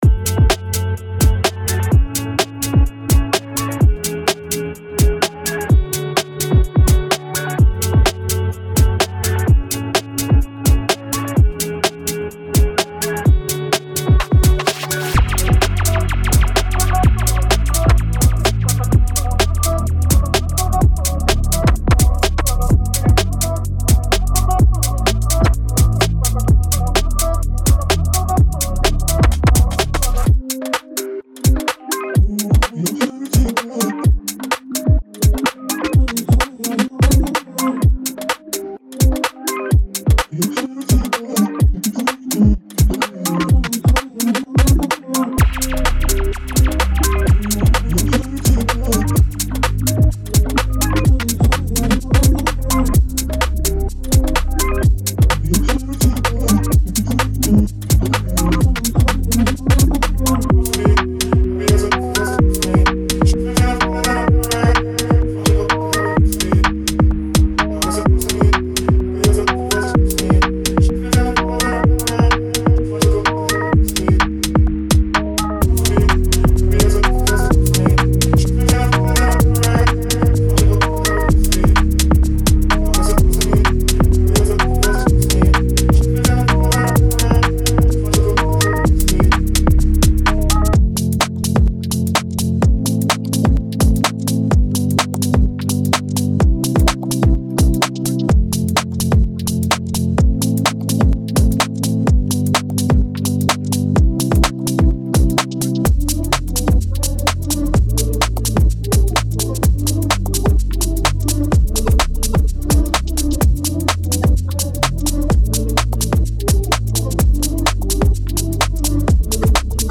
Genre:Garage
このパックには、125から130BPMの範囲で展開する際立ったワンショットとループのコレクションが収録されています。